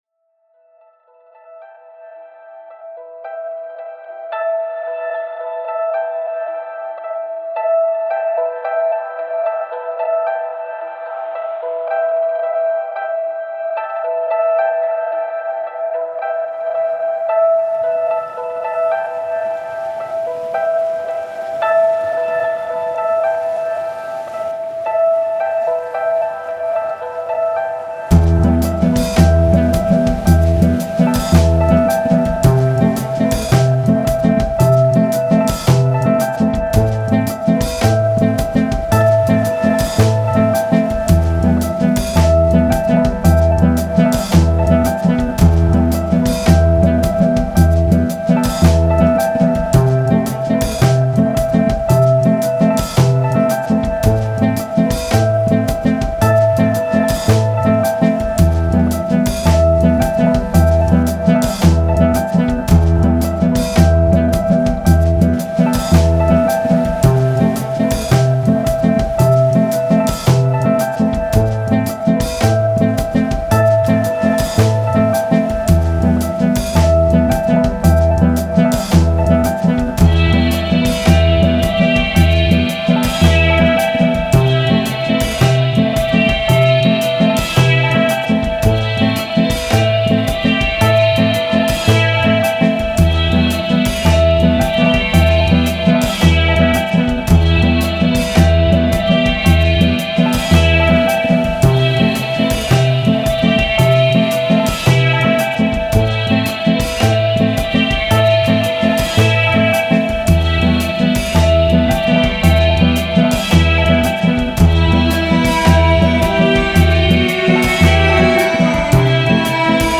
Greek composer